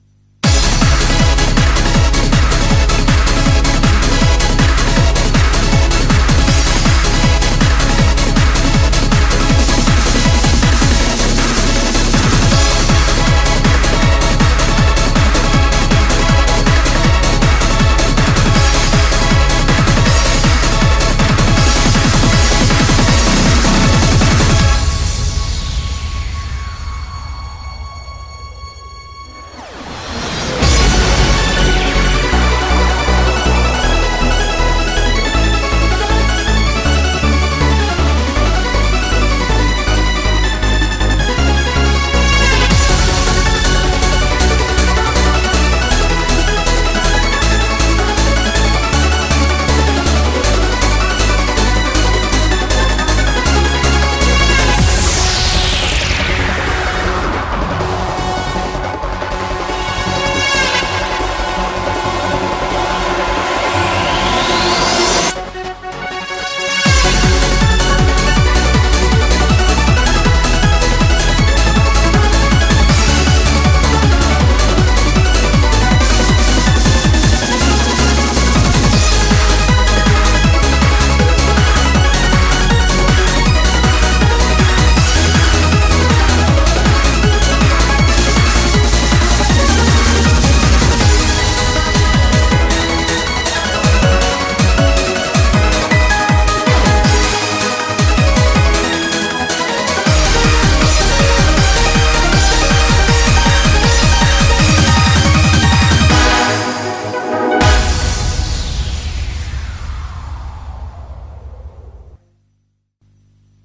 BPM159
Audio QualityPerfect (Low Quality)